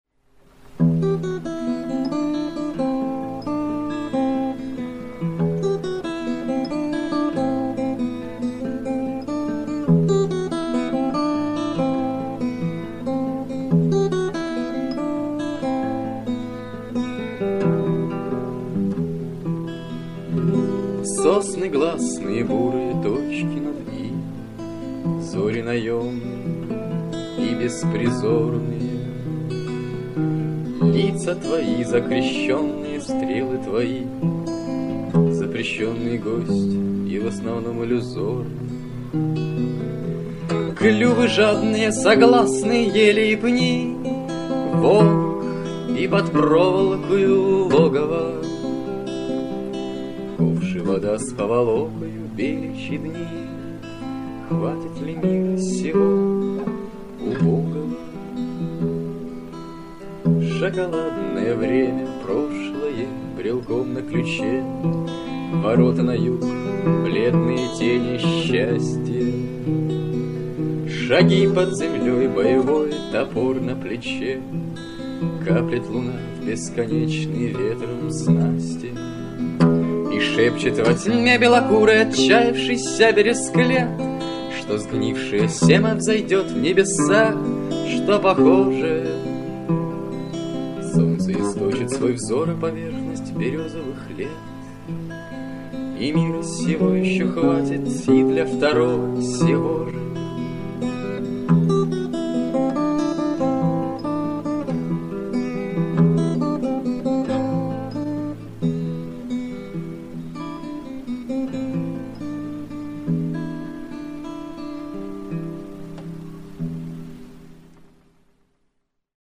На одной старой кассете в мамином архиве нашёлся целый комплект всякого добра из 1995 года.